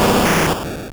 Cri d'Arbok dans Pokémon Or et Argent.